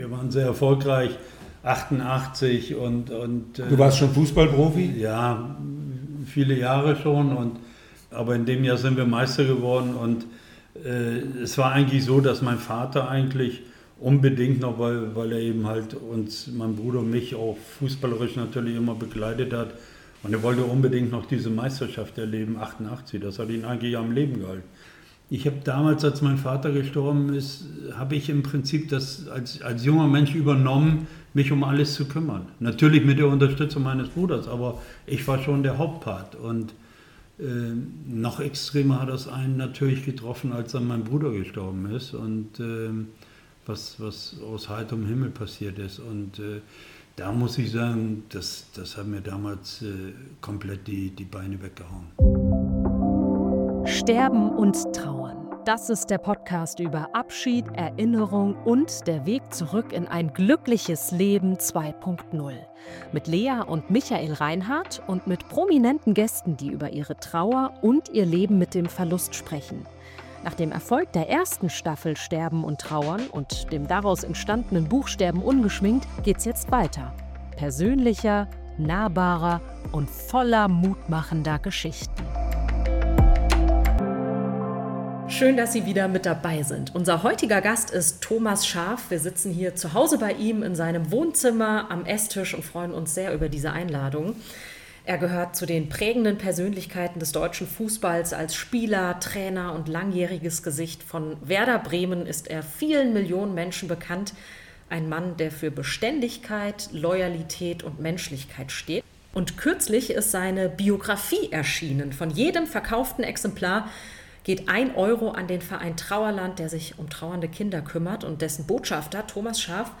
In dieser letzten Podcast-Folge unserer zweiten Staffel treffen wir Thomas Schaaf, eine echte Ikone des deutschen Fußballs – und zugleich einen Menschen, der mit bemerkenswerter Offenheit über Trauer, Verlust und Zusammenhalt spricht.